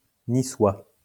Niçard (Classical orthography), nissart/Niçart (Mistralian orthography, IPA: [niˈsaʀt]), niçois (/nˈswɑː/ nee-SWAH, French: [niswa]